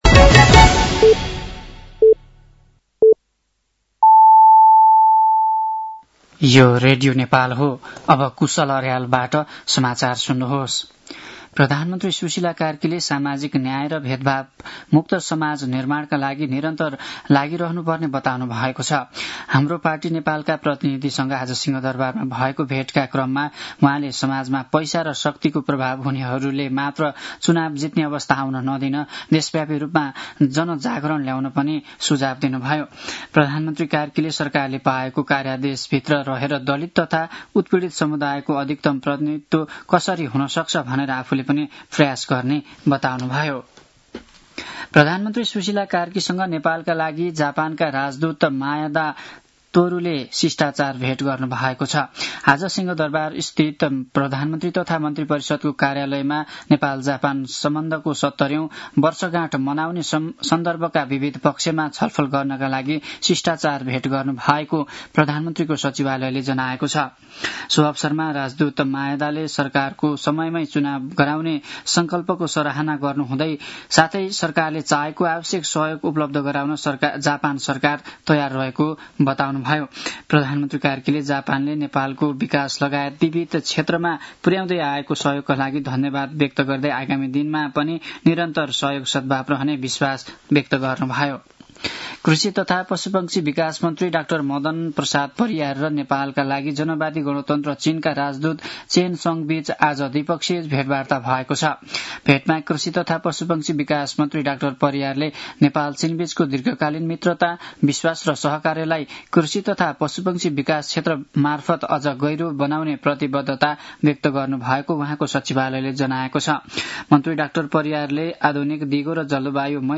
साँझ ५ बजेको नेपाली समाचार : १९ मंसिर , २०८२
5-pm-nepali-news-8-19.mp3